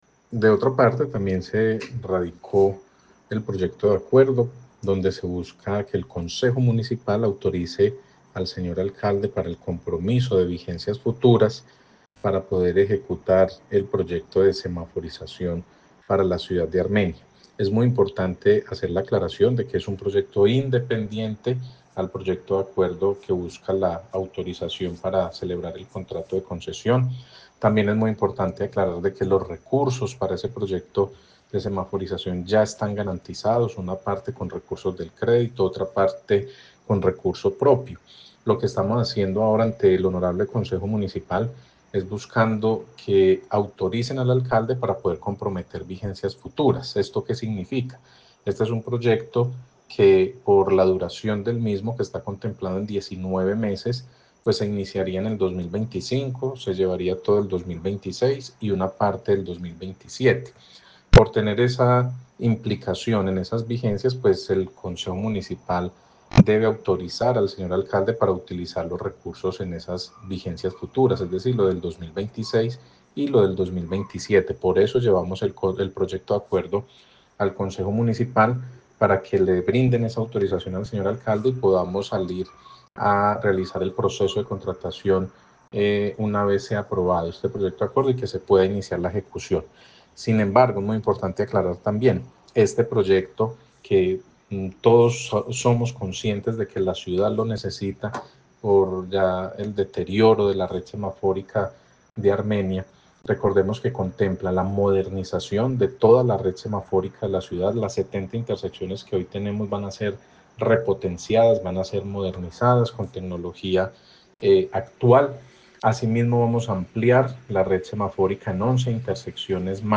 Audio del Secretario de la Setta Daniel Jaime Castaño Calderón:
Daniel-Jaime-Castano-Secretario-SETTA.mp3